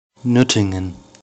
Nürtingen (German: [ˈnʏʁtɪŋən]
Nürtingen.ogg.mp3